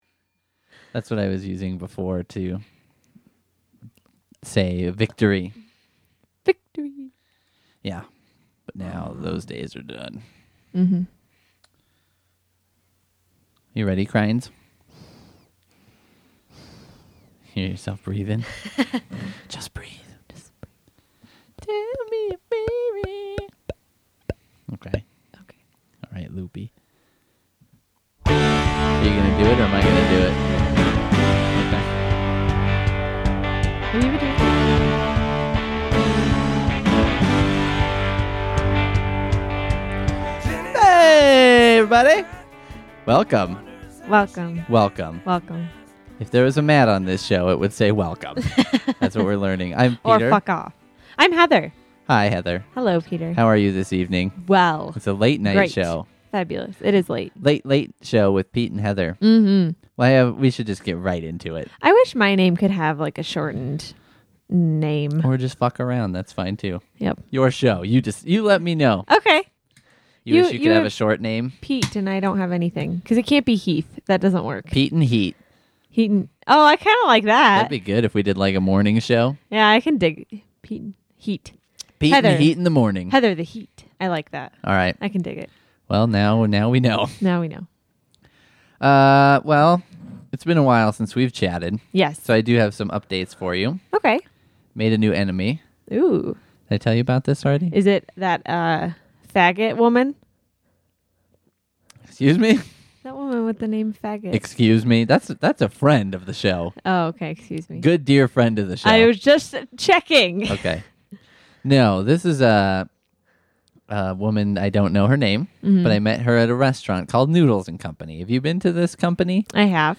A mellow show with fashion advice and new enemies.